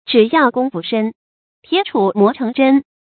注音：ㄓㄧˇ ㄧㄠˋ ㄍㄨㄙ ㄈㄨ ㄕㄣ ，ㄊㄧㄝ ˇ ㄔㄨˇ ㄇㄛˊ ㄔㄥˊ ㄓㄣ